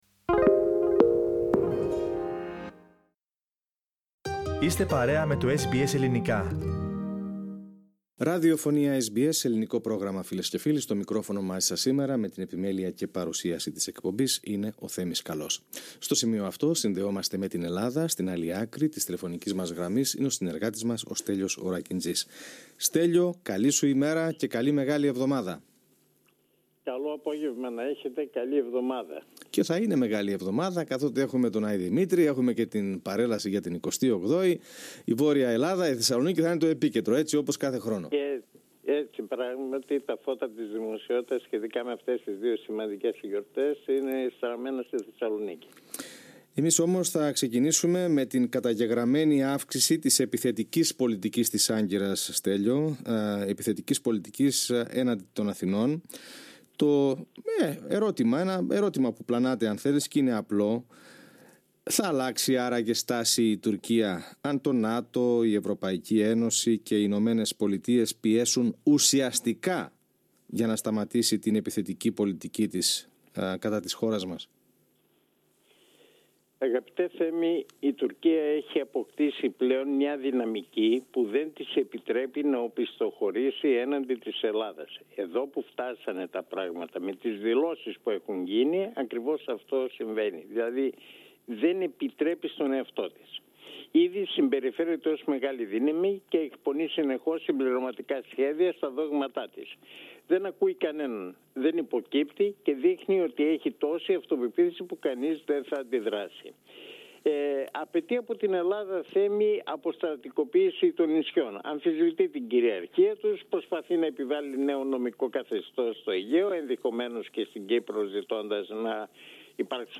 Ακούστε την εβδομαδιαία ανταπόκριση από την Ελλάδα.